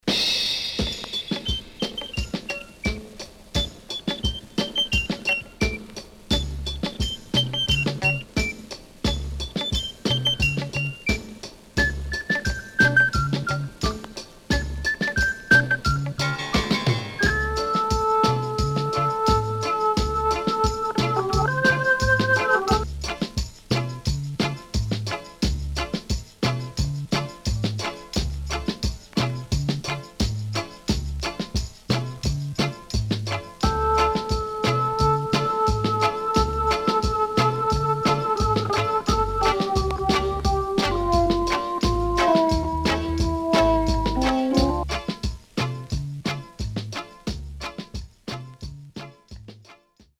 riddim
SIDE A:ヒスノイズ入ります。少しチリノイズ入りますが良好です。